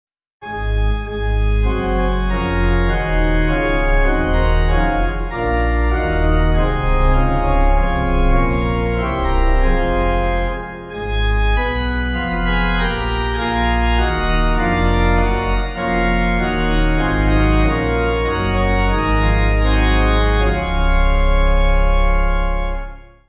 (CM)   6/Db